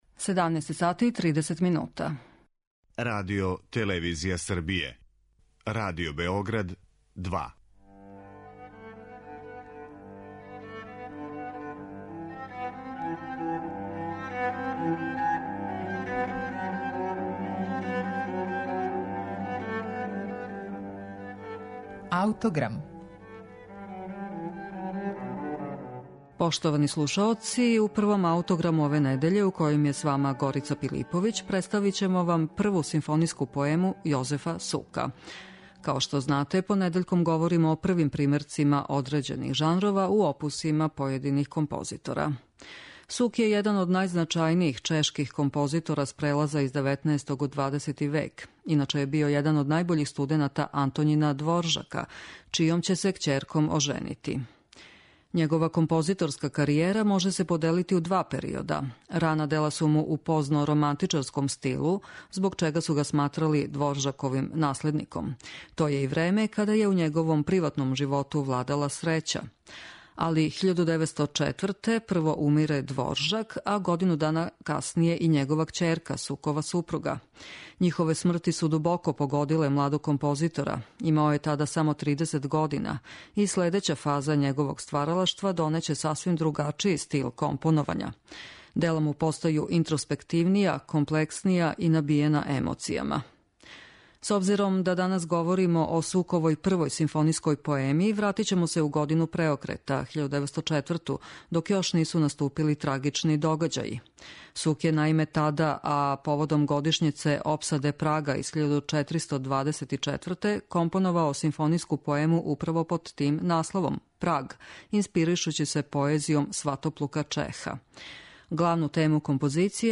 симфонијску поему